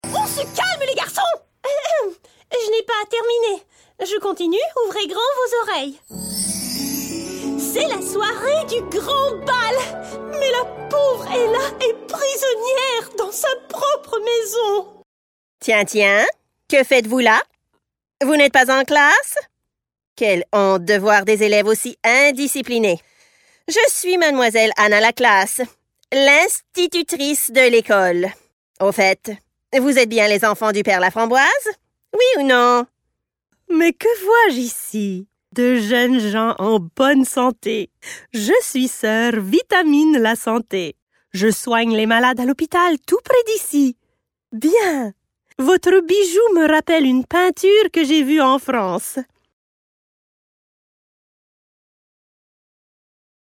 Animation - FR